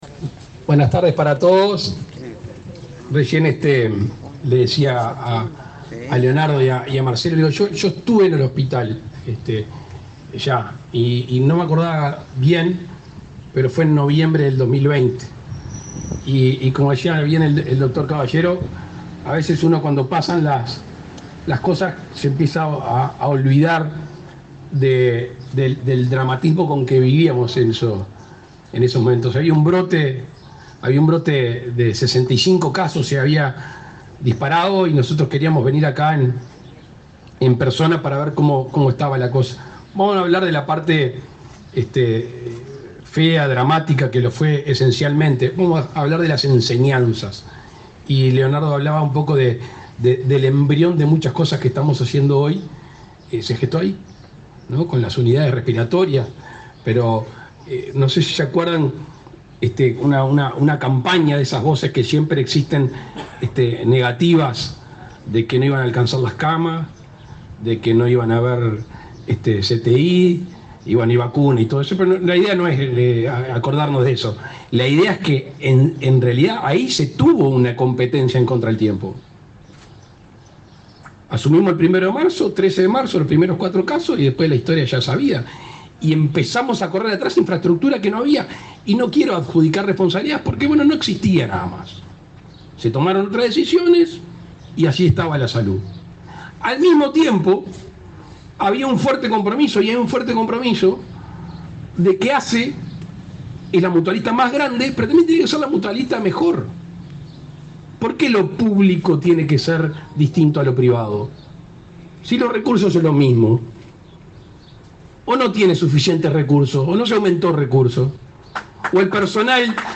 Palabras del presidente Luis Lacalle Pou en el hospital de Melo
El presidente Luis Lacalle Pou participó, este viernes 22 en Melo, de la inauguración del CTI del hospital local.